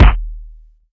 KICK.94.NEPT.wav